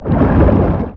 Sfx_creature_chelicerate_exoattack_loop_water_os_01.ogg